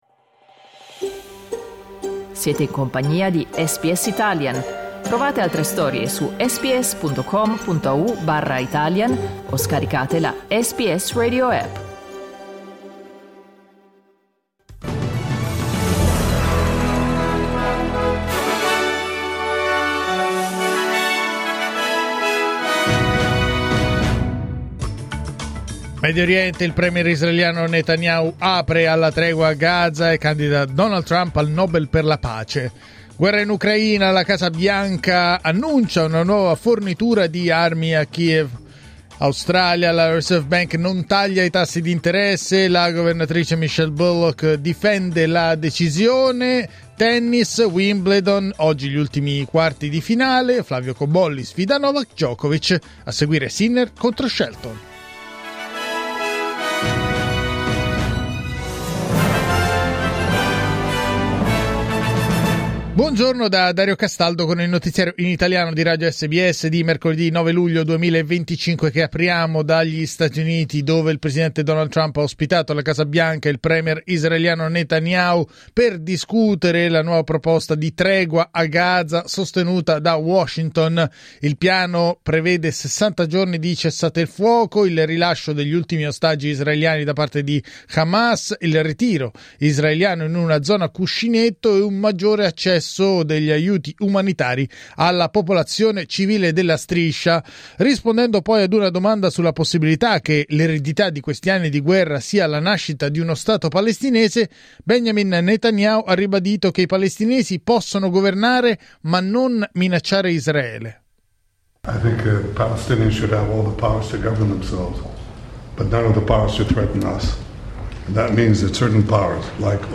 Giornale radio mercoledì 9 luglio 2025
Il notiziario di SBS in italiano.